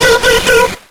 Cri de Démanta dans Pokémon X et Y.